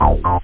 303-bass.echo2
Amiga 8-bit Sampled Voice
1 channel
303-bass.mp3